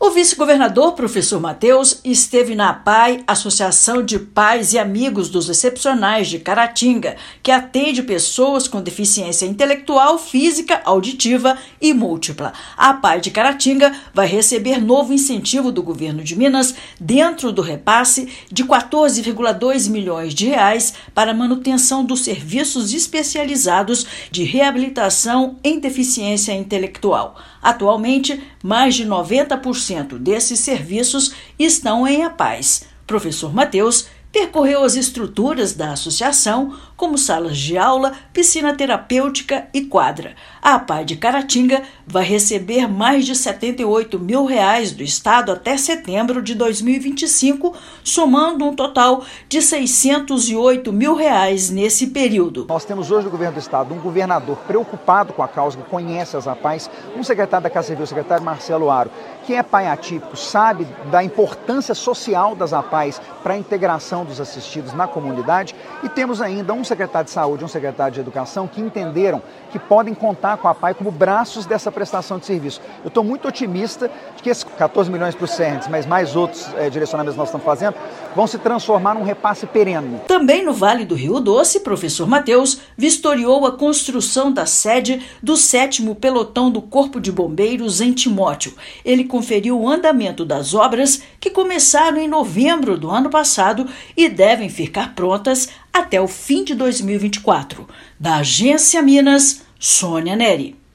[RÁDIO] Governo do Estado vistoria Apae de Caratinga e reforça compromisso com serviços de reabilitação
No Vale do Rio Doce, vice-governador também supervisiona evolução das obras da sede do sétimo Pelotão do Corpo de Bombeiros, em Timóteo. Ouça matéria de rádio.